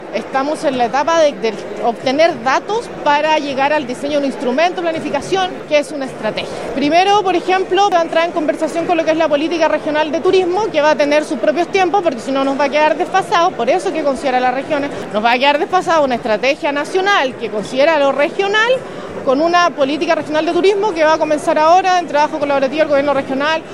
Fue en el Terminal Internacional y Centro de Vinculación Ciudad Puerto, de la capital regional, donde se reunieron en la ceremonia oficial, miembros de Empormontt, autoridades políticas y empresarios del turismo.